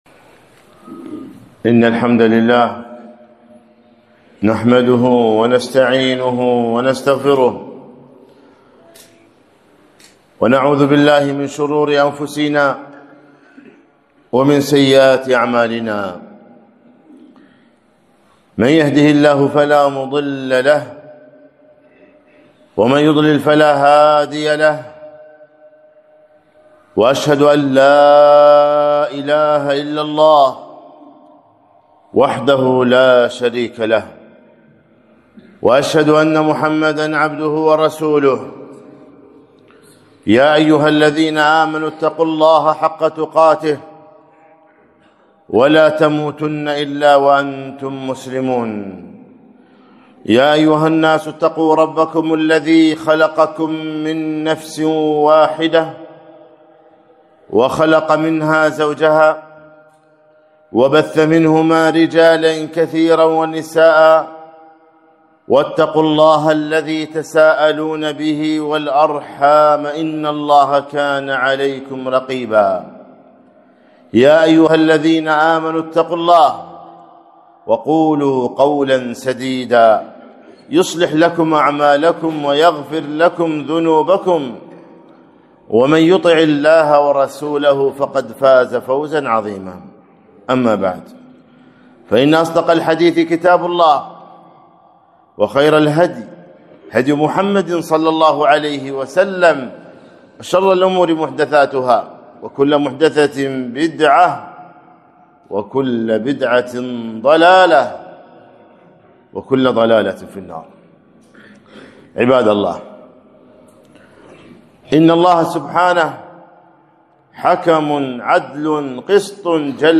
خطبة - الله حكم عدل